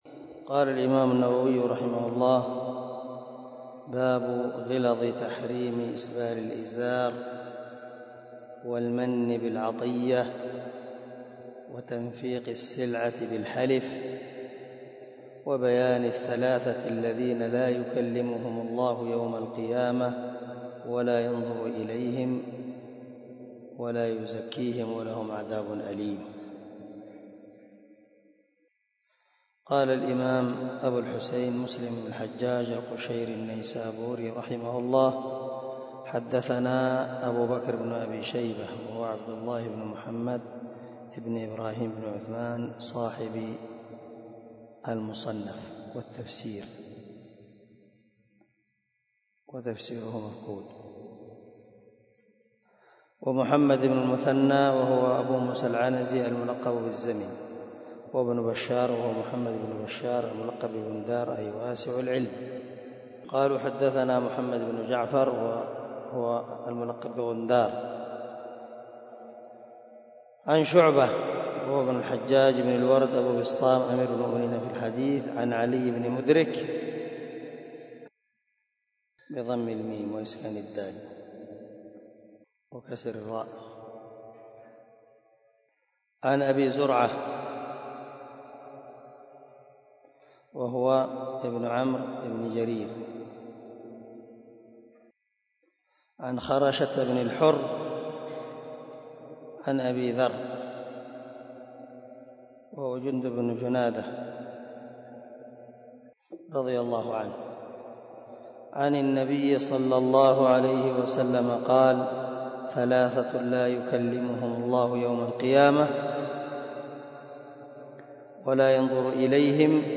074الدرس 73 من شرح كتاب الإيمان حديث رقم ( 106 ) من صحيح مسلم